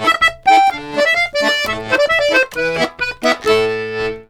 Index of /90_sSampleCDs/USB Soundscan vol.40 - Complete Accordions [AKAI] 1CD/Partition C/04-130POLKA
C130POLKA1-R.wav